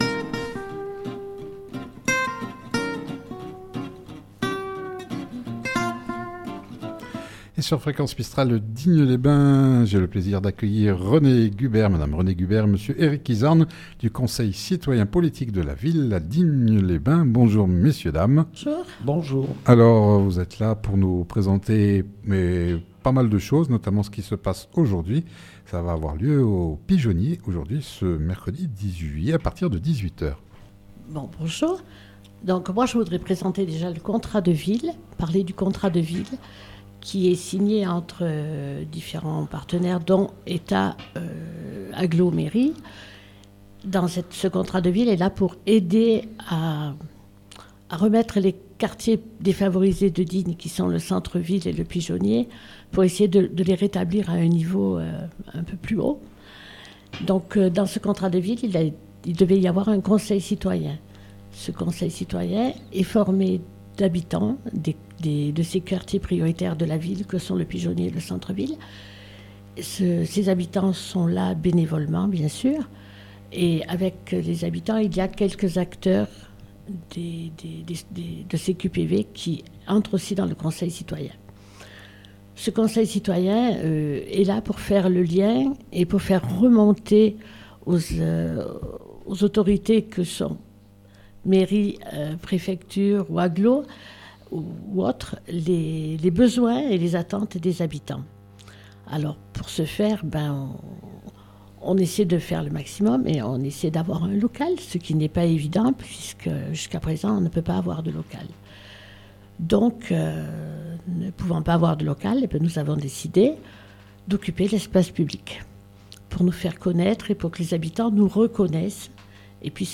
sont venus en direct dans les studio de fréquence mistral nous parler des soirées d'échange et de convivialité autour d'un apéritif. Certains quartiers à Digne sont reconnus pour être défavorisés et pour être reconnus comme tels, les critères sont sévères car un nombre de personnes étant dans la précarité (isolés, aux revenus modestes...) doivent y habiter.